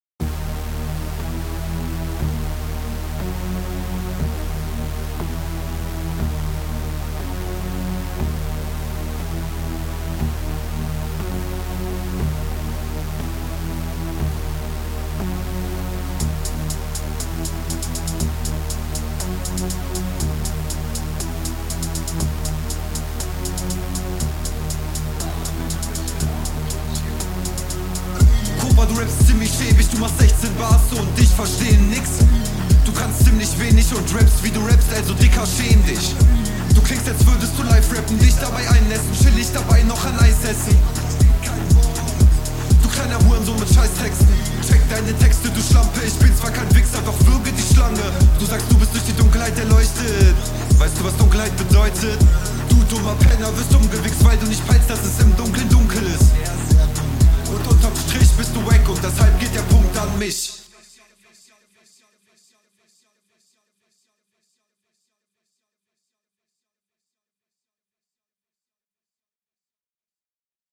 Flow: schöner Flow, geht gut nach vorne, Pausensetzung auch gut.
Flow: Dein Flow passt nice auf dem Beat .